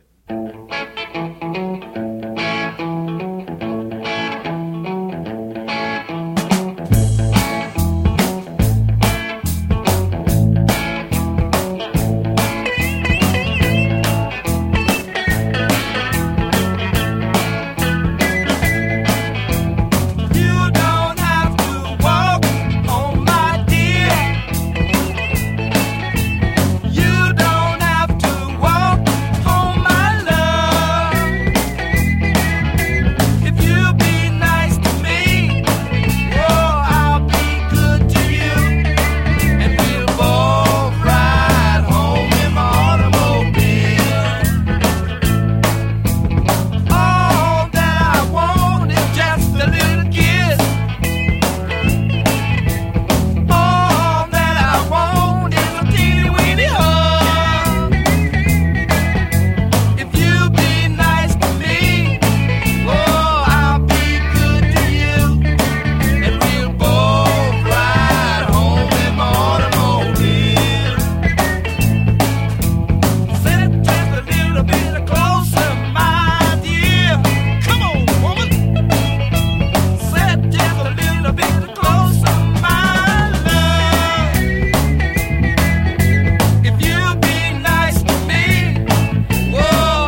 [ FUNK | SOUL ]